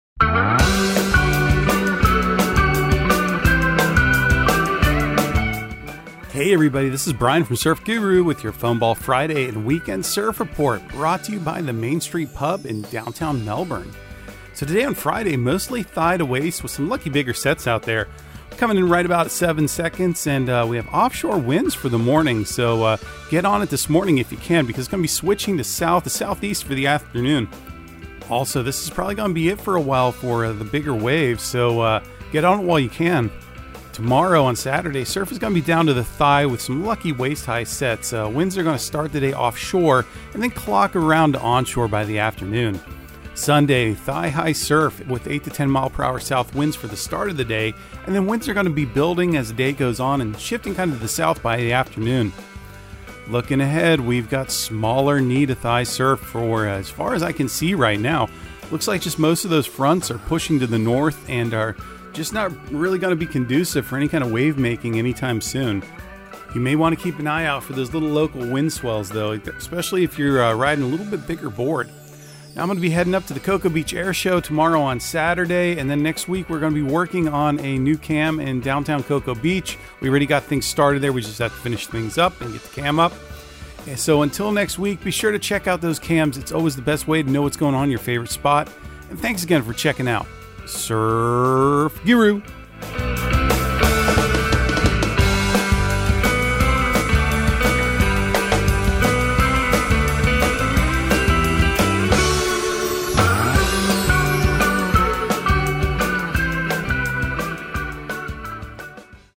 Surf Guru Surf Report and Forecast 04/14/2023 Audio surf report and surf forecast on April 14 for Central Florida and the Southeast.